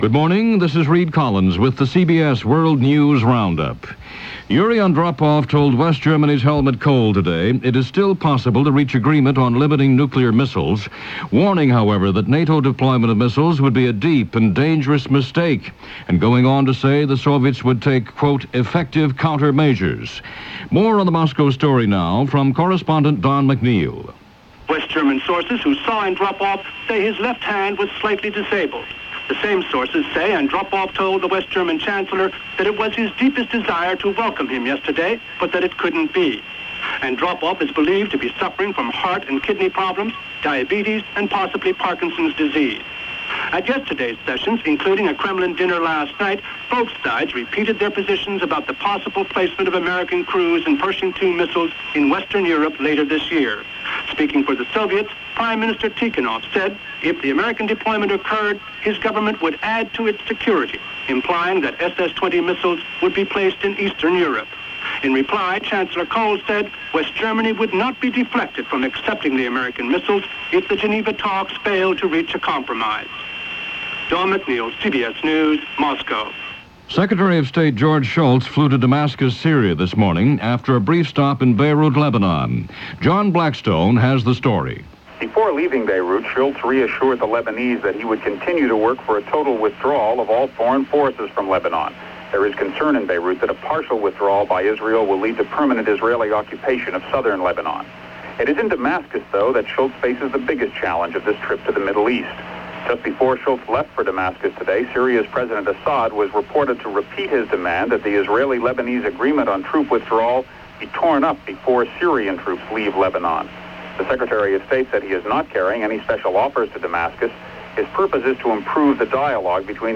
9:00 am news